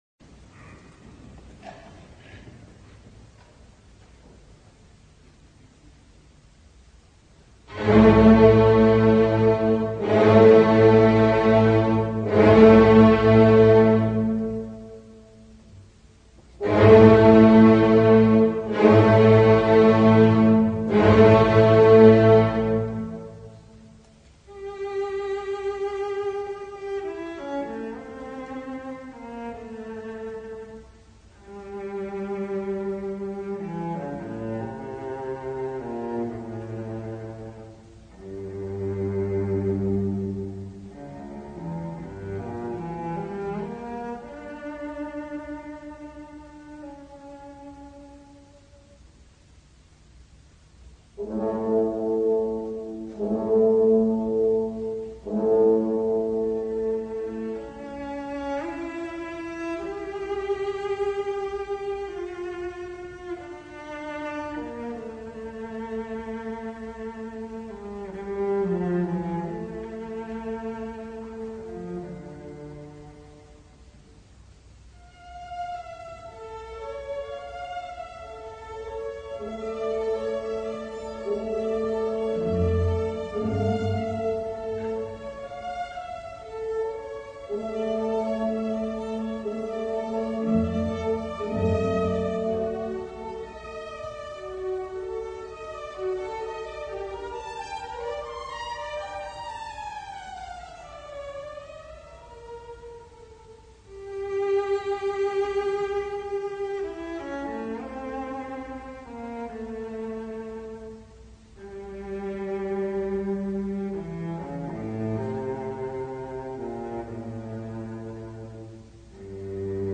per basso e banda